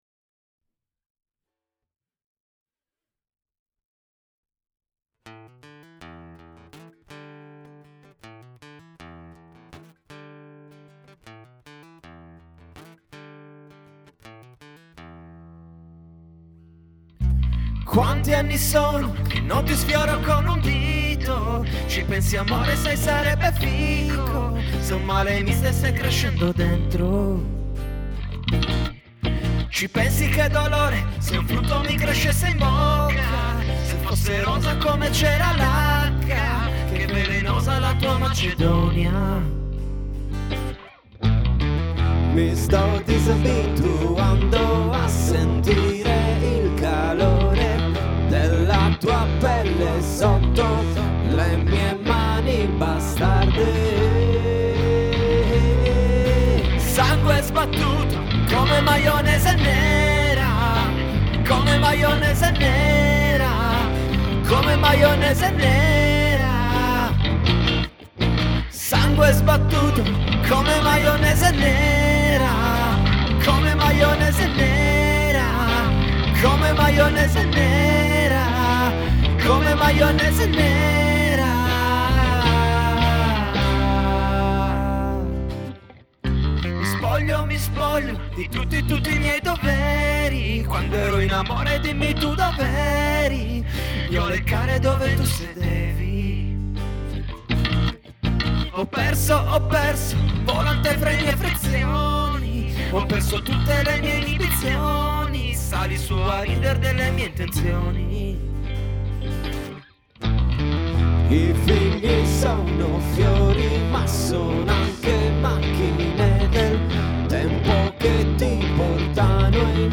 bass gtr